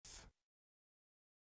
Audio of the phoneme for Commonscript letter 38 (pronounced by male).
Phoneme_(Commonscript)_(Accent_0)_(38)_(Male).mp3